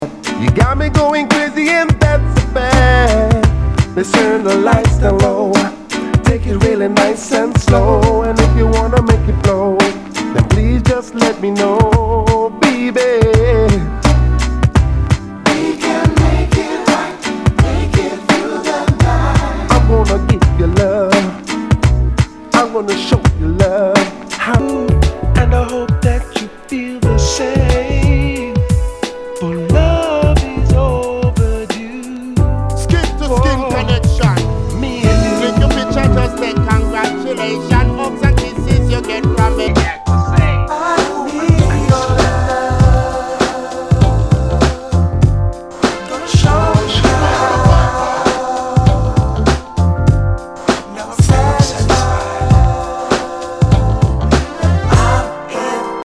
SOUL/FUNK/DISCO
UKソウル / ダウンテンポ！